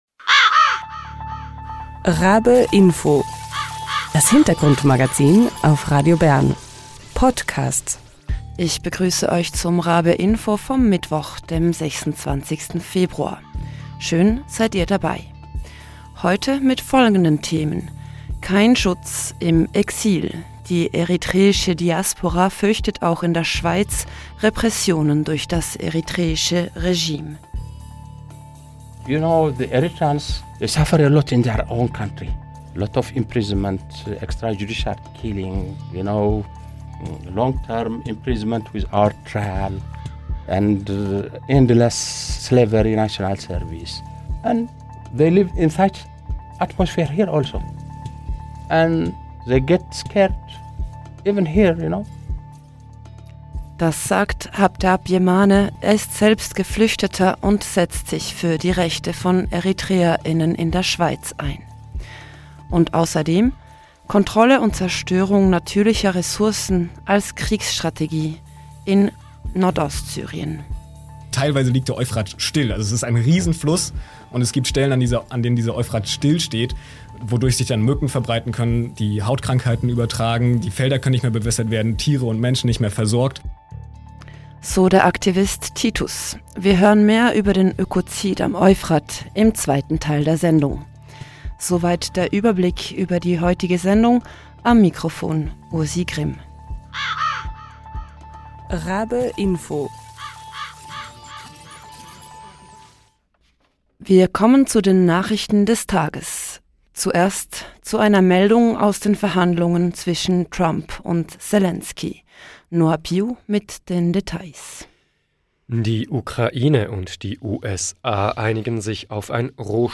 Beschreibung vor 1 Jahr Der lange Arm der eritreischen Repression reicht bis in die Schweiz. Geflüchtete fürchten Einschüchterungen und Repression auch hierzulande und engagieren sich zunehmend gegen die Diktatur im Herkunftsland und für ihre Rechte in der Schweiz. Im Interview